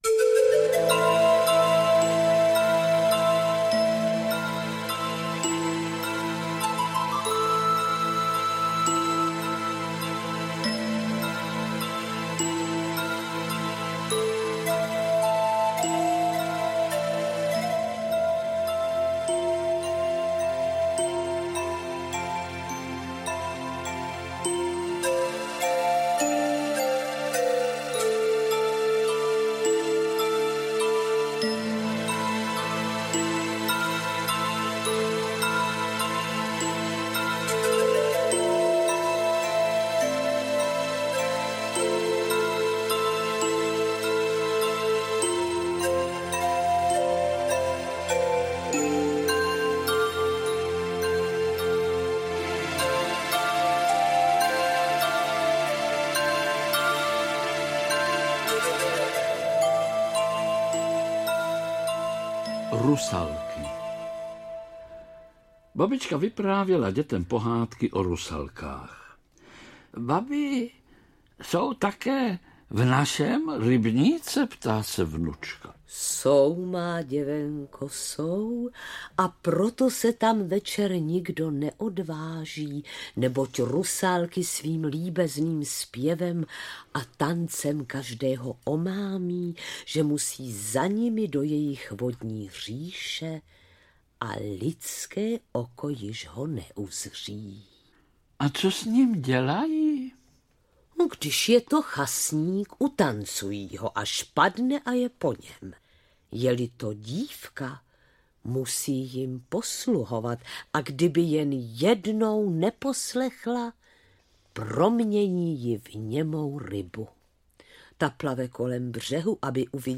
O rusalkách, vodníkovi a světýlkách. Pohádky ze Šumavy II - Adolf Daněk - Audiokniha
• Čte: Vlastimil Brodský, Jiřina Jirásková